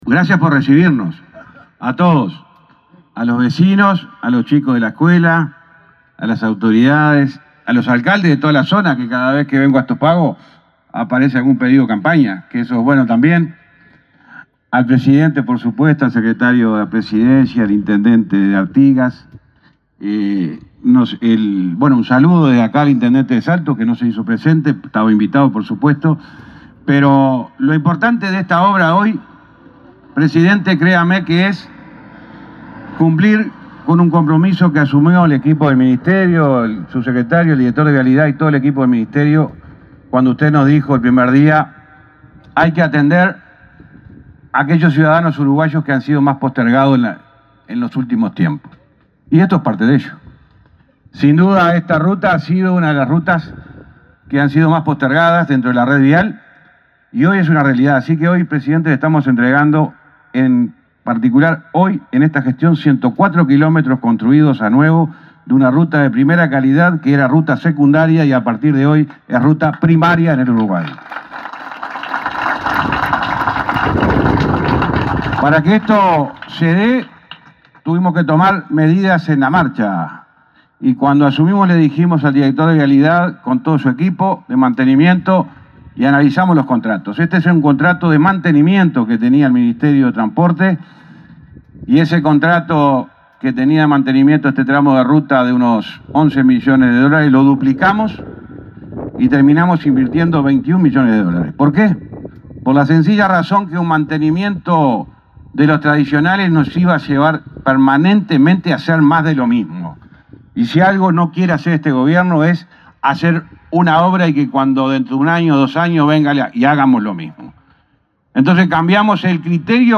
Palabras del ministro de Transporte, José Luis Falero